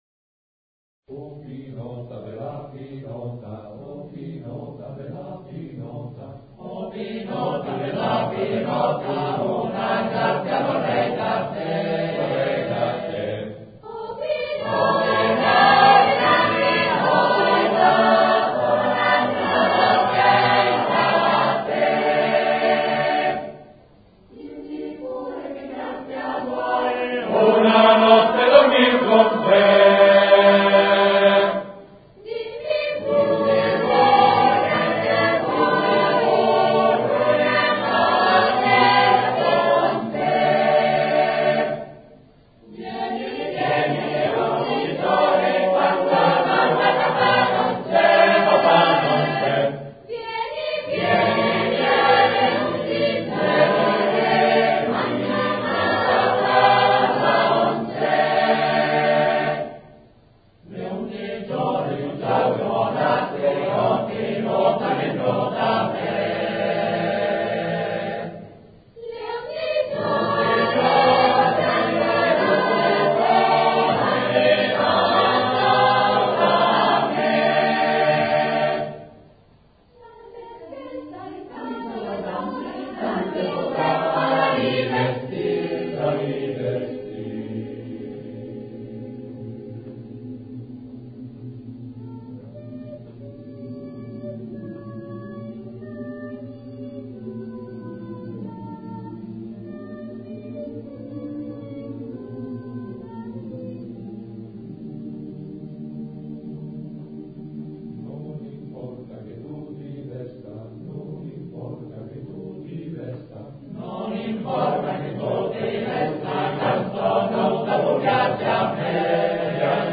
Ricerca, elaborazione, esecuzione di canti popolari emiliani
voci: voci virili